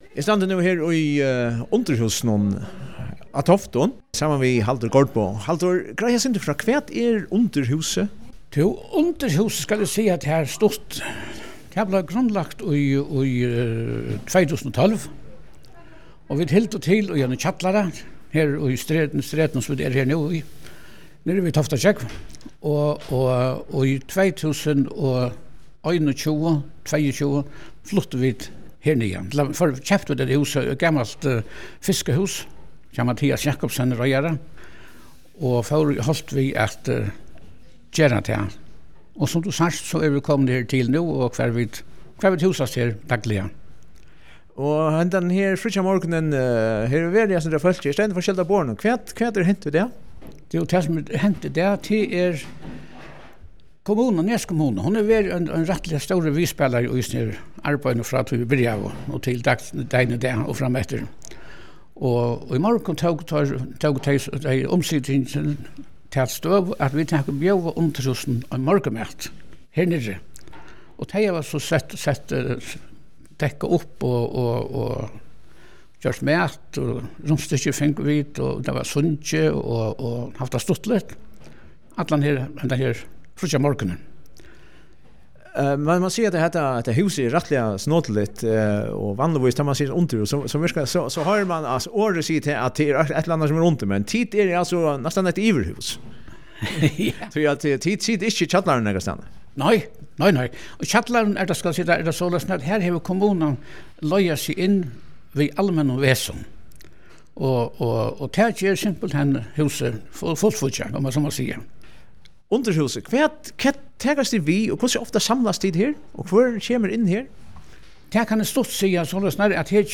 Í morgun hevði kommunan boðið til morgunmat og hugna og handaði kommunan teimum eina klokku úr føroyskum gróti. Vit fingu orðið á borgarstjóran í Nes Kommunu, Súna í Hjøllum, og spurdu hann millum annað hvat hann og familjan eta á jólum.